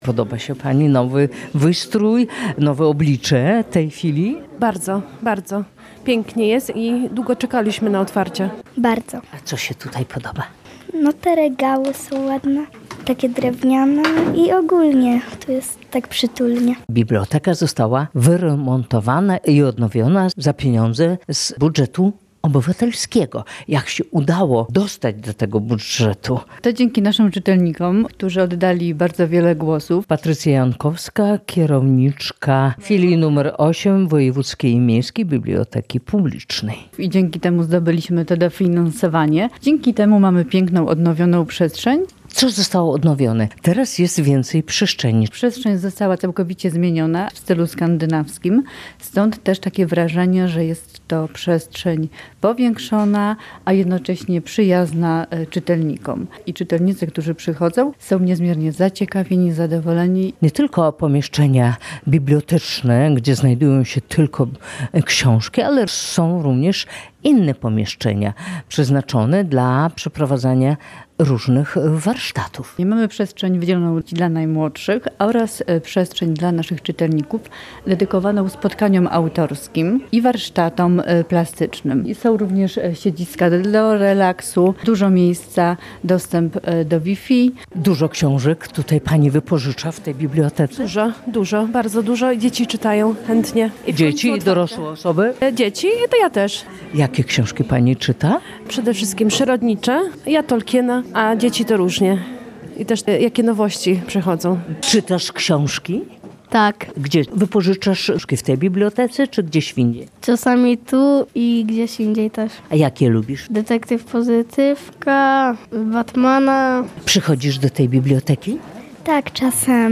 Nowe oblicze Filii nr 8 Wojewódzkiej i Miejskiej Biblioteki Publicznej w Rzeszowie • Relacje reporterskie • Polskie Radio Rzeszów